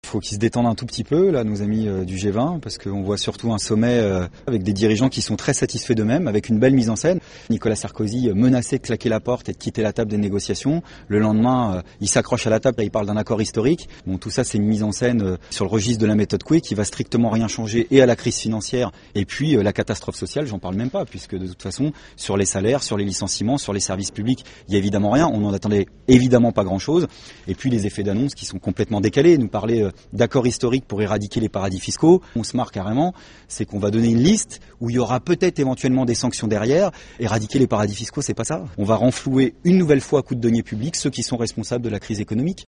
Entretien diffusé sur Radio France Internationale, le 3 avril 2009.